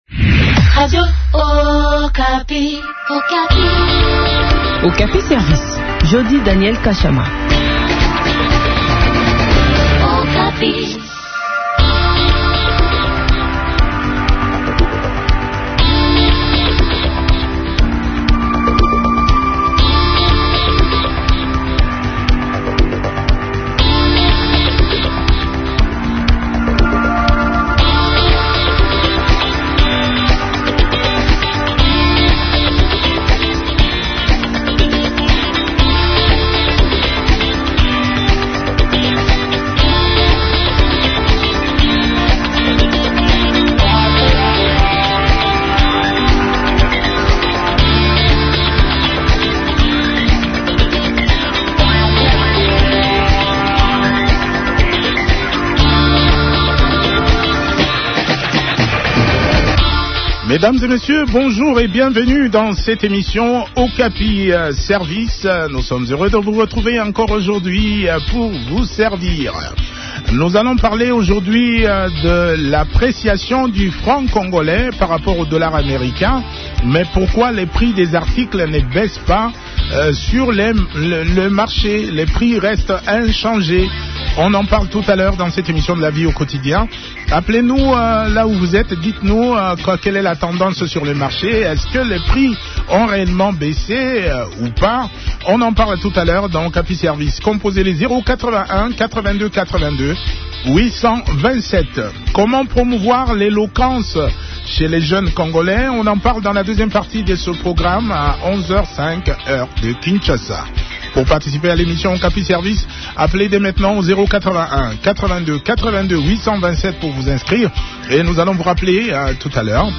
Pour les invités d’Okapi Service, le gouvernement devrait prendre certaines mesures, notamment :